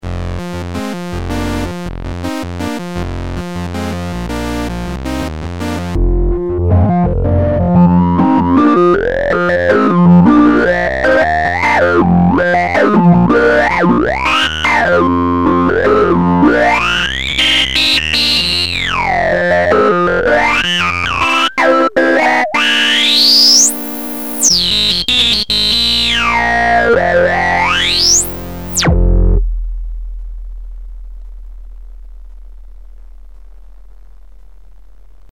4. A simple hand played polyphonic blues part with a soft saw-tooth synth.
Demo_saw_rock.mp3